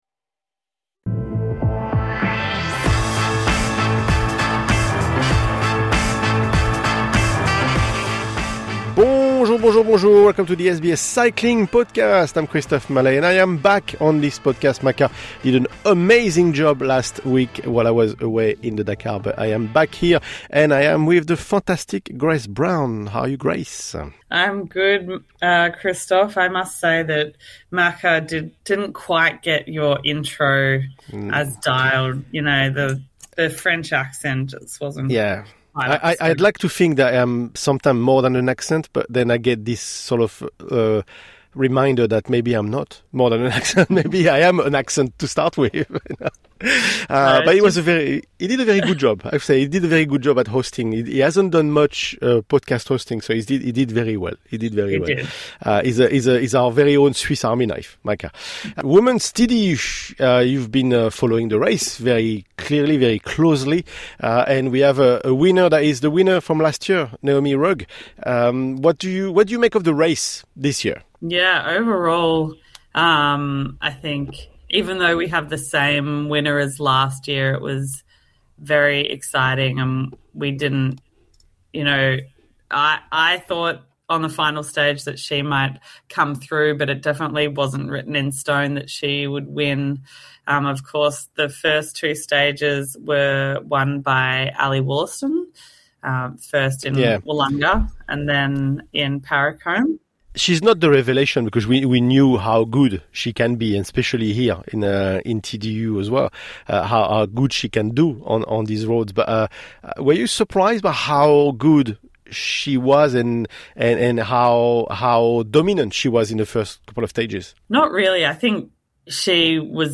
The hosts unpack the key moments of the race, highlighting the tactical depth and intensity that continue to push the event to new heights within the women’s calendar.